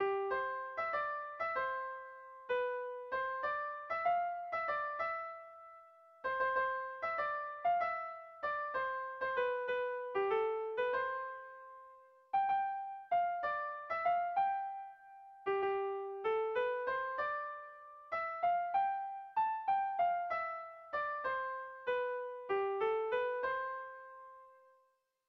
Irrizkoa
Larraun < Larraunaldea < Iruñeko Merindadea < Nafarroa < Euskal Herria
Zortziko txikia (hg) / Lau puntuko txikia (ip)
ABDE